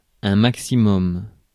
Ääntäminen
IPA : /ˈsiː.lɪŋ/